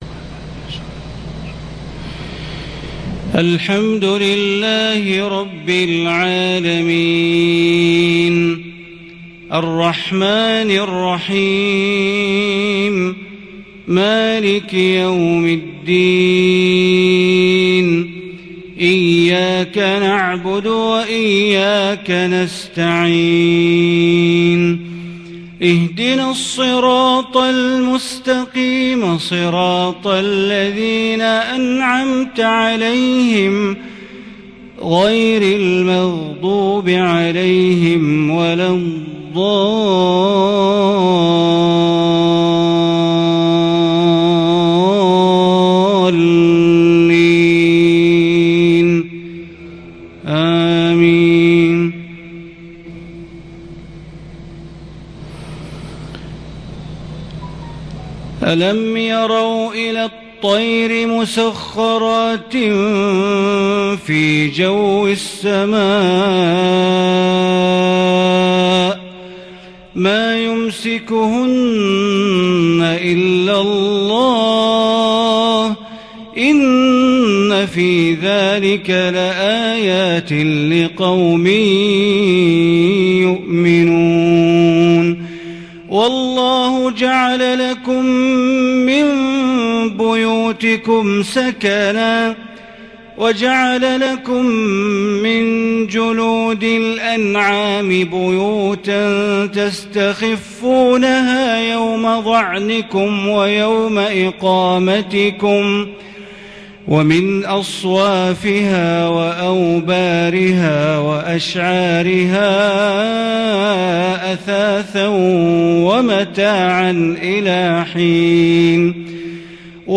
صلاة العشاء من سورتي النحل و الملك ١-٥-١٤٤٢هـ | > 1442 هـ > الفروض - تلاوات بندر بليلة